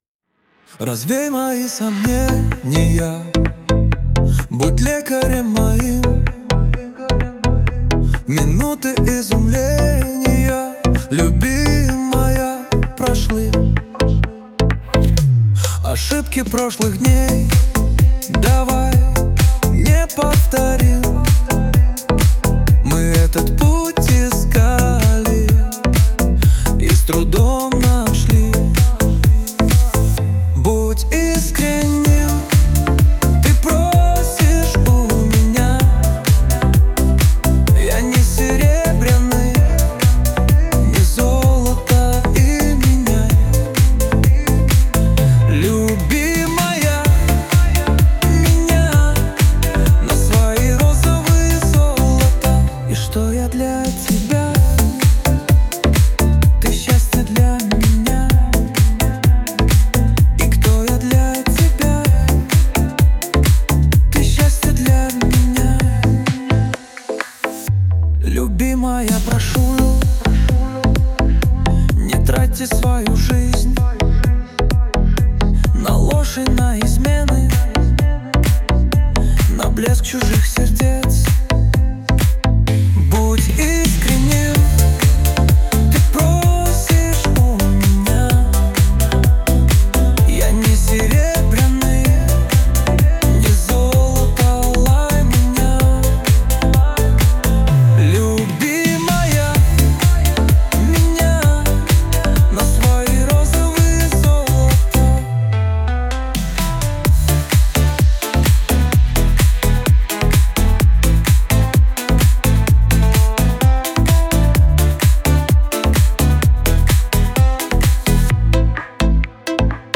Для песеп использую ИИ.
ТИП: Пісня
СТИЛЬОВІ ЖАНРИ: Ліричний